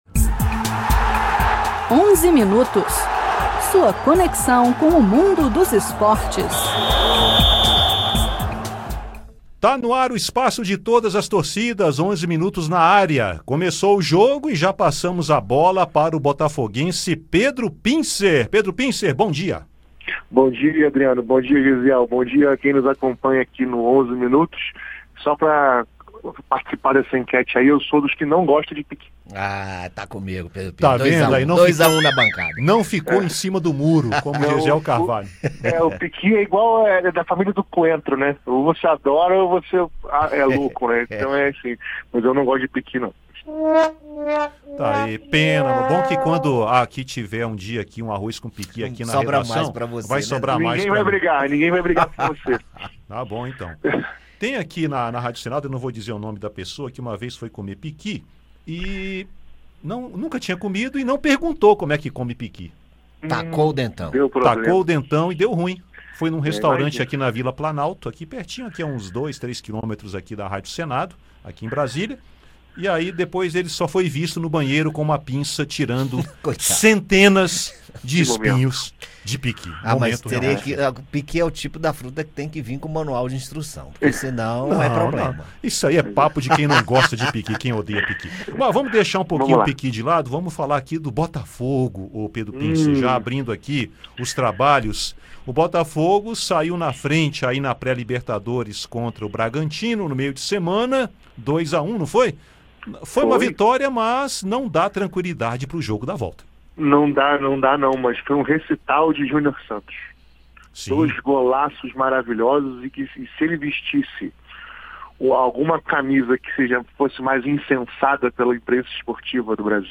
Nos comentários com o jornalista e botafoguense